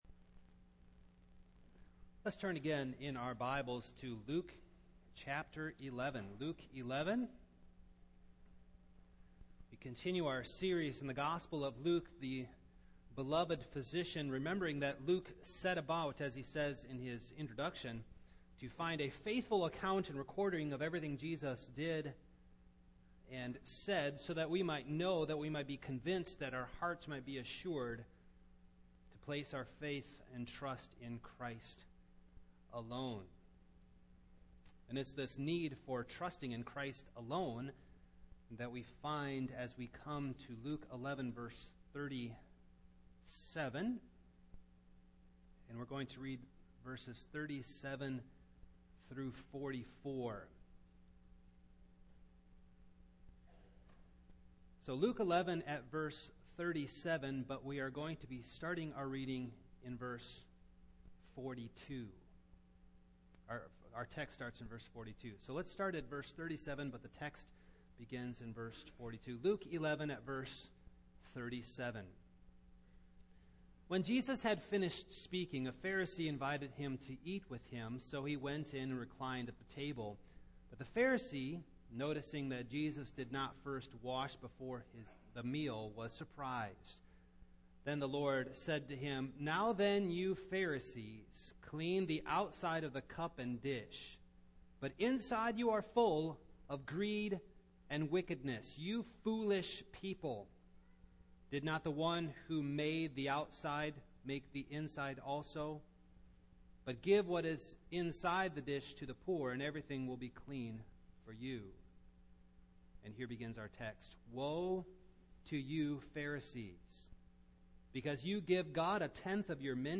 Passage: Luke 11:37-44 Service Type: Morning